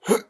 snd_hurt.ogg